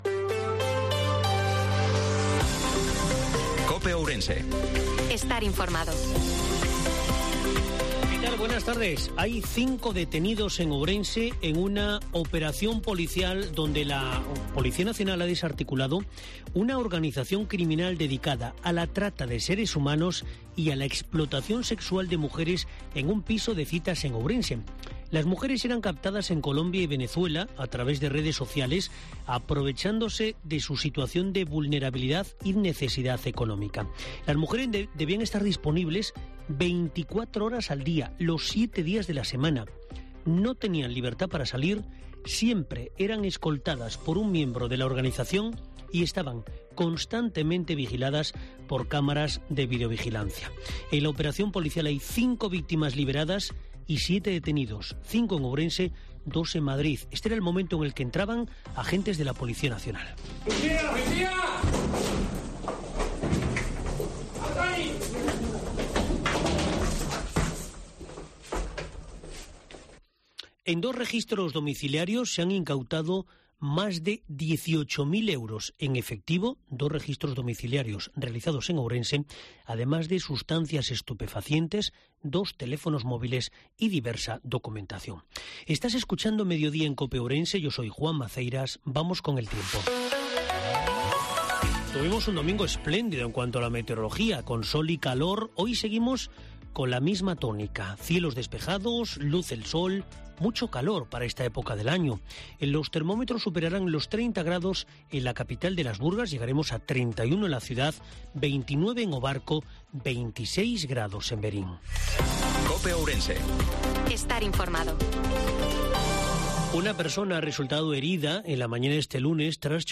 INFORMATIVO MEDIODIA COPE OURENSE-17/04/2023